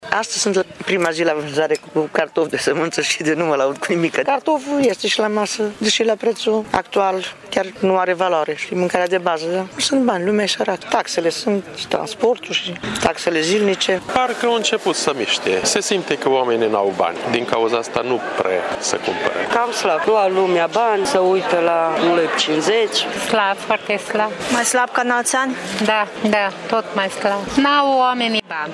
Ei spun că a crescut prețul de producție, iar prețul de vânzare prea nu le acoperă pierderile.